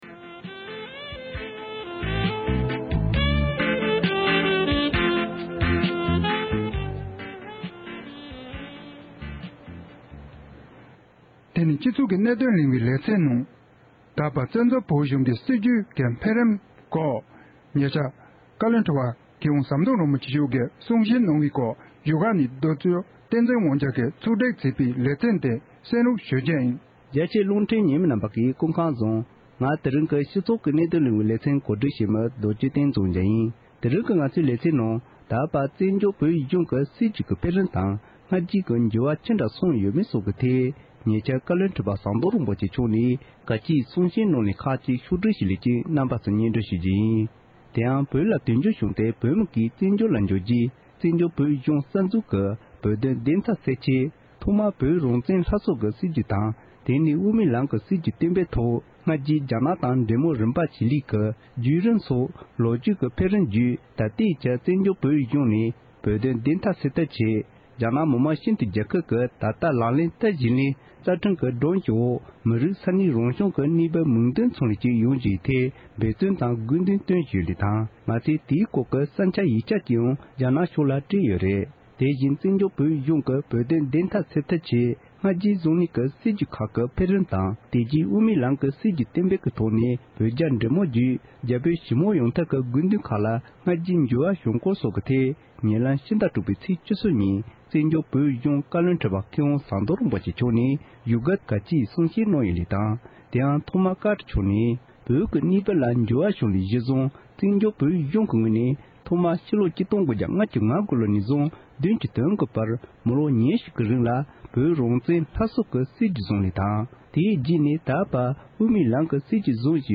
བཀའ་བློན་ཁྲི་པ་མཆོག་གིས་བཙན་བྱོལ་བོད་གཞུང་གི་སྲིད་བྱུས་ཀྱི་འཕེལ་རིམ་དང་སྔ་ཕྱི་འགྱུར་བ་ཅི་འདྲ་ཕྱིན་ཡོད་མེད་ཐད་གསུང་བཤད་གནང་བ
སྒྲ་ལྡན་གསར་འགྱུར། སྒྲ་ཕབ་ལེན།